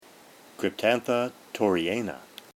Pronunciation/Pronunciación:
Cryp-tán-tha  tor-re-yà-na